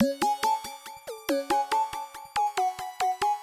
Metro_loopC#m (4).wav